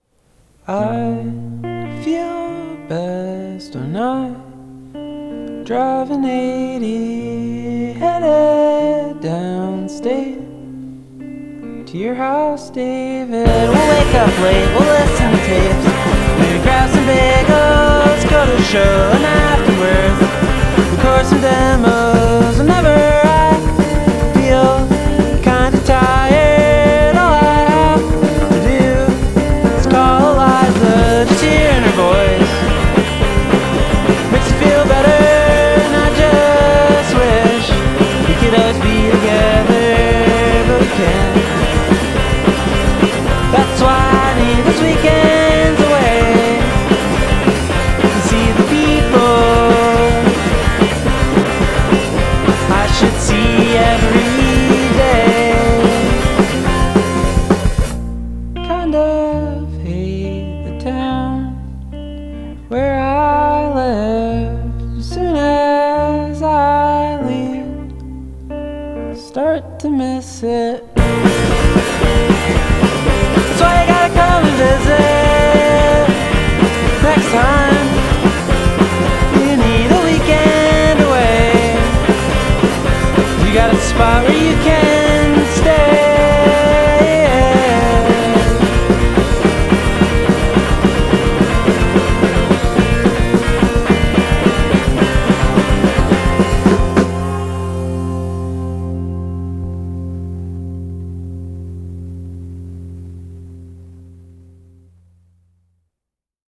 "twee punx"